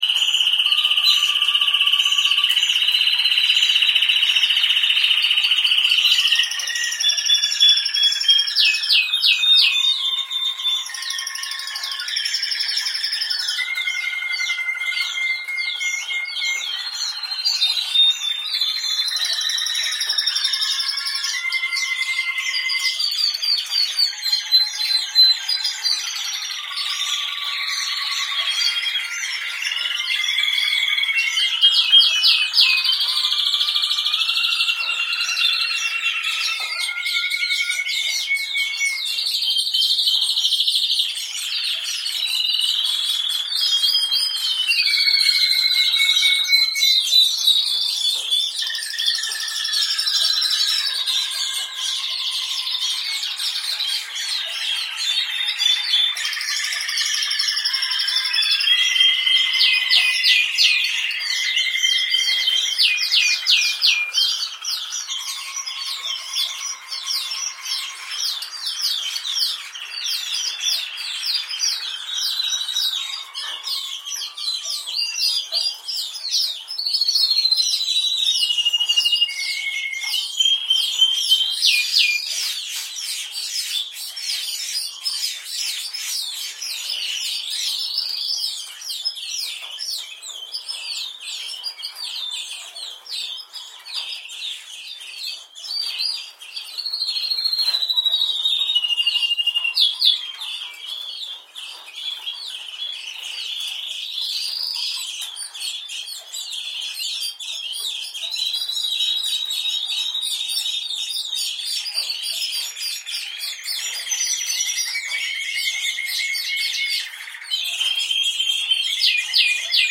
جلوه های صوتی
دانلود صدای قناری از ساعد نیوز با لینک مستقیم و کیفیت بالا
برچسب: دانلود آهنگ های افکت صوتی انسان و موجودات زنده